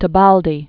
(tə-bäldē, tĕ-), Renata 1922-2004.